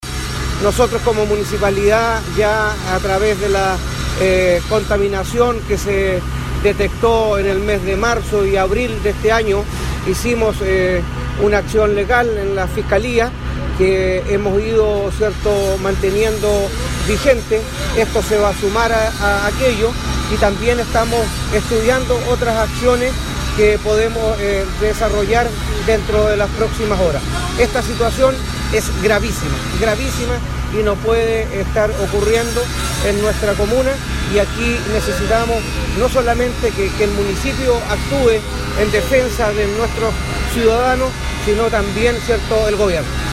Las declaraciones las realizó la mañana de este miércoles, desde el lugar donde se tuvo que intervenir el remodelado paseo público, para encontrar la tubería que se rompió este martes y derramó miles de litros de aguas servidas al mar.